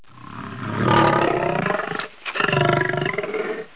دانلود صدای حیوانات جنگلی 33 از ساعد نیوز با لینک مستقیم و کیفیت بالا
جلوه های صوتی